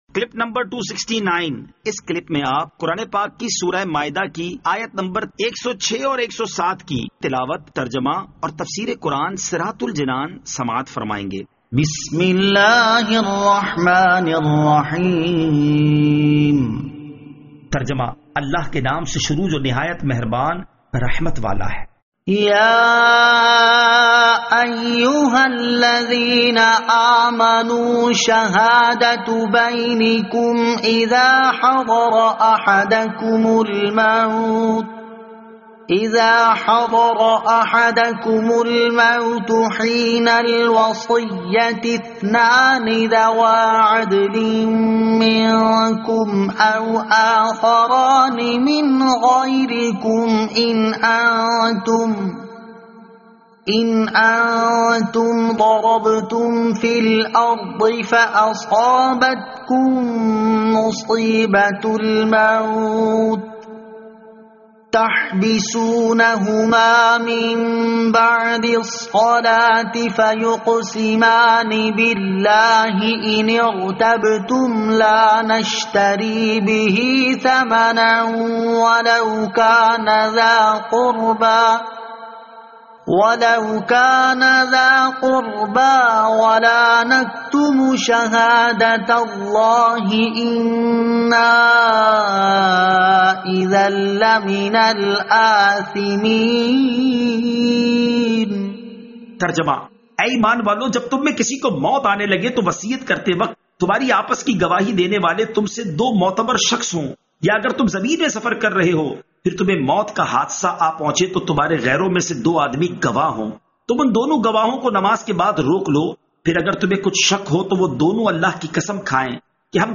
Surah Al-Maidah Ayat 106 To 107 Tilawat , Tarjama , Tafseer